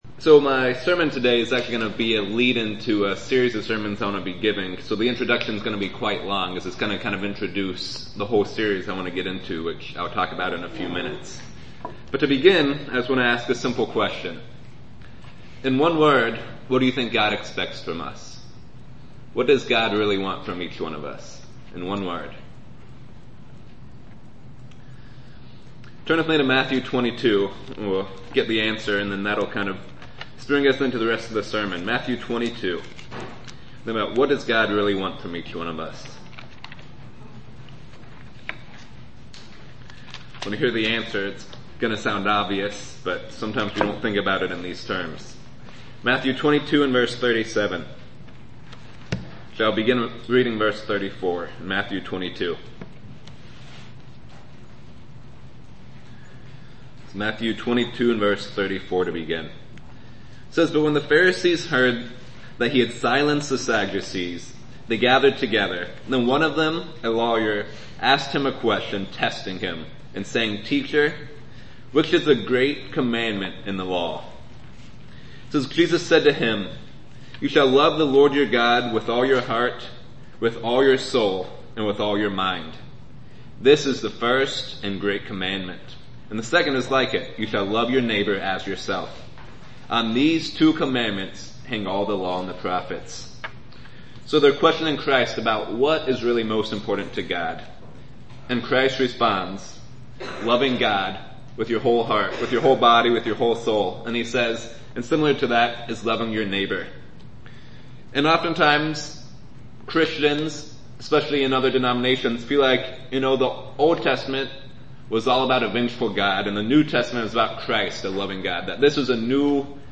We are going to take a deeper looking into how we express our love when we obey each of His 10 commandments. In today's sermon we will cover having "No Other God Before Me".